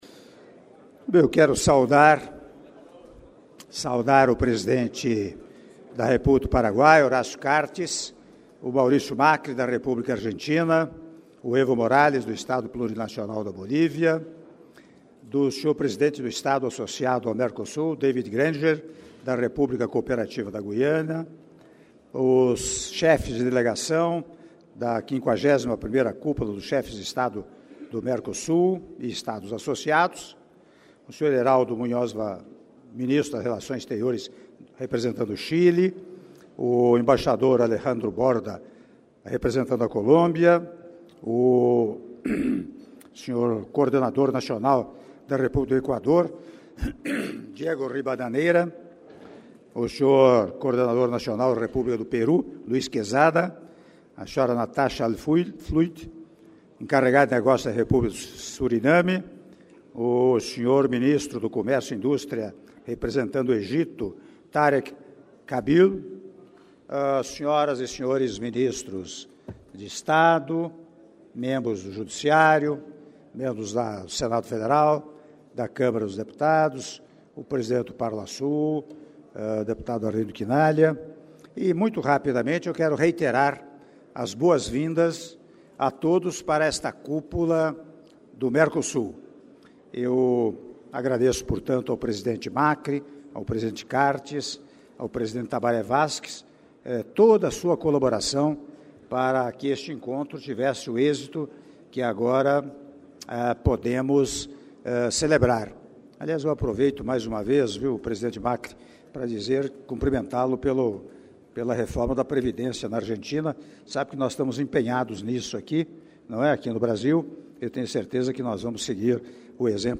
Áudio do brinde do Presidente da República, Michel Temer, durante almoço em homenagem aos Chefes de Delegação da LI Cúpula de Chefes de Estado do Mercosul e Estados Associados - Palácio Itamaraty (05min32s)